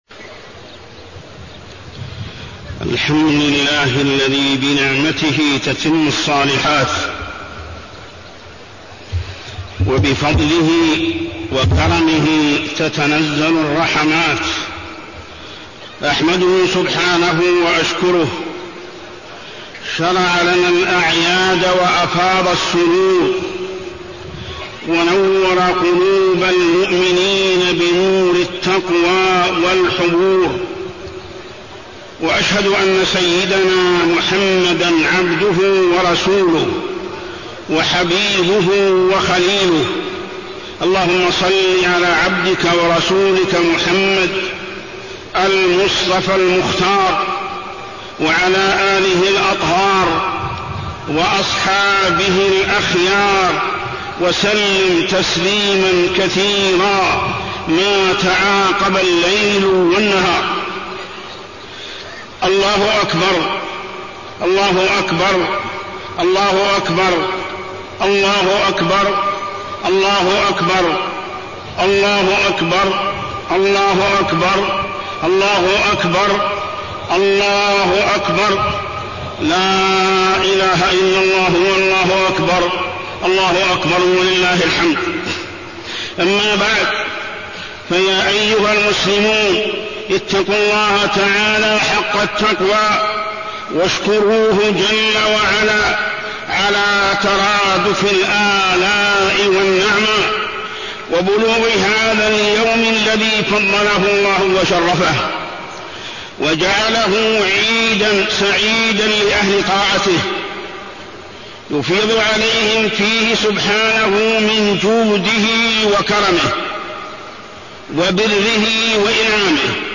تاريخ النشر ١ شوال ١٤٢١ هـ المكان: المسجد الحرام الشيخ: محمد بن عبد الله السبيل محمد بن عبد الله السبيل خطبة عيد الفطر-الحذر من الإشاعات ونقل الإخبار